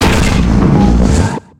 Cri de Golemastoc dans Pokémon X et Y.